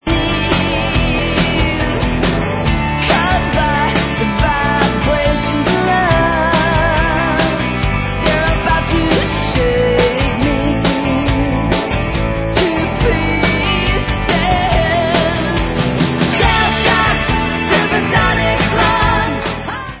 sledovat novinky v kategorii Rock